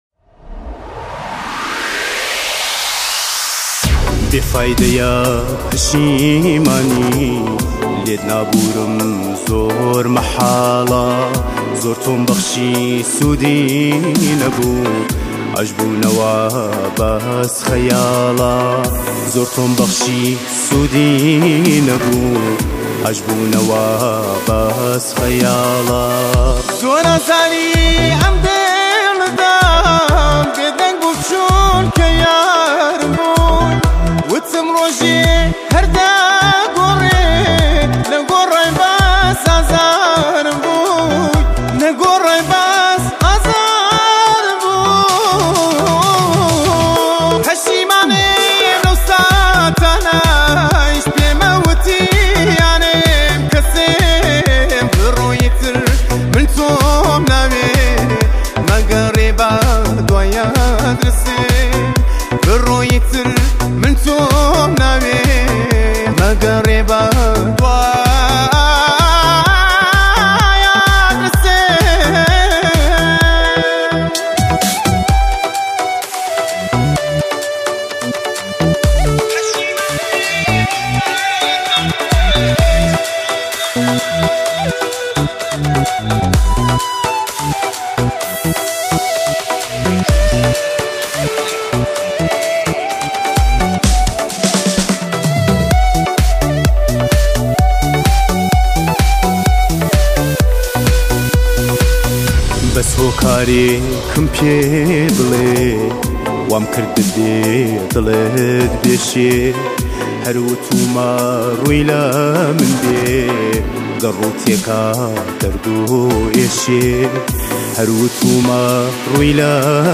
گورانی کوردی